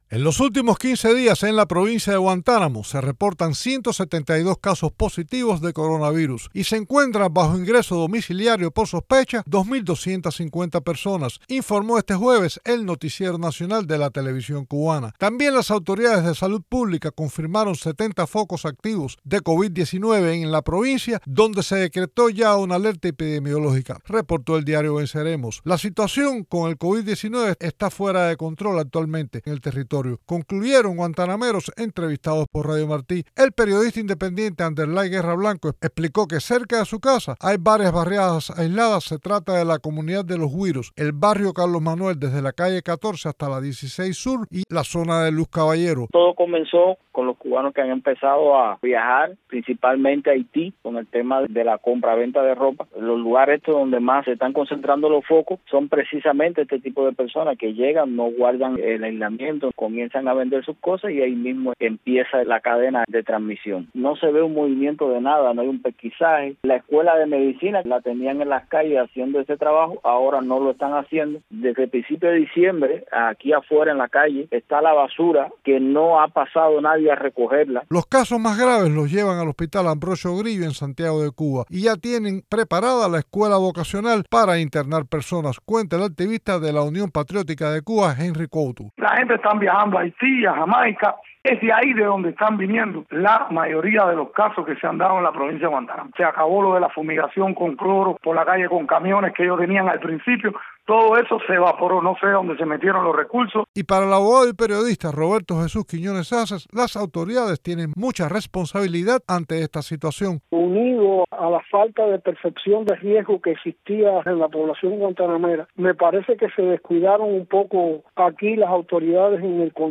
Guantanameros entrevistados por Radio Televisión Martí, concluyen que actualmente la situación con la pandemia en el territorio está fuera de control.